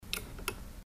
Звуки швейной машинки